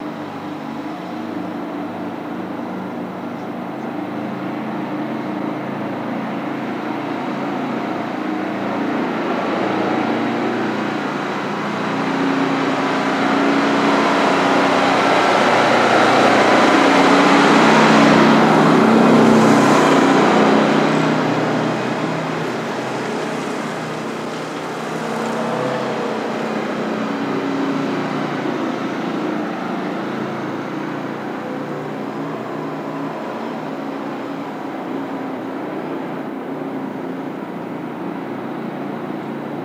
Highwayman Truck, Medium By